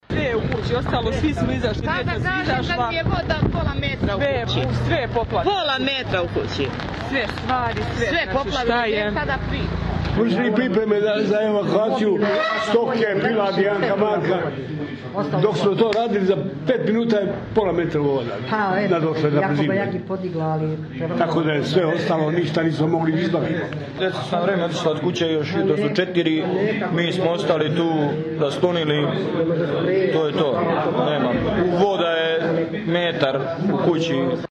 Građani čije je kuće poplavila reka Čemernica